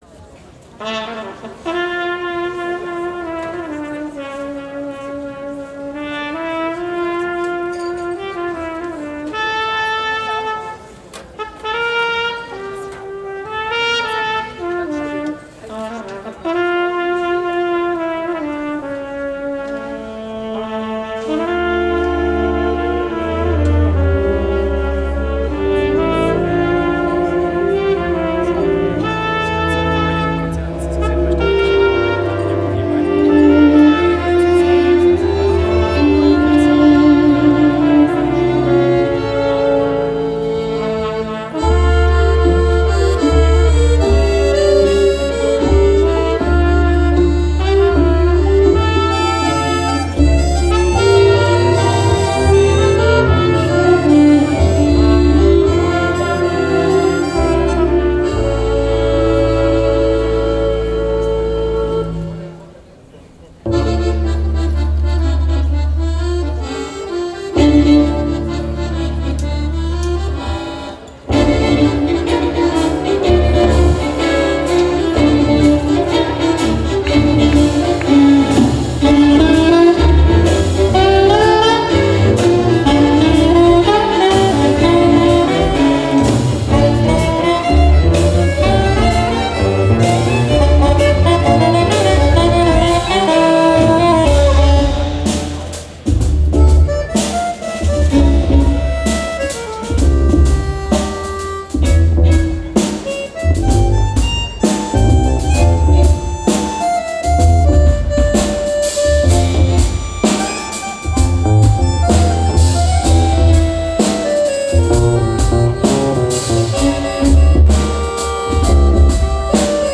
· Genre (Stil): Jazz
· Kanal-Modus: stereo · Kommentar